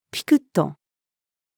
with-a-twitch-female.mp3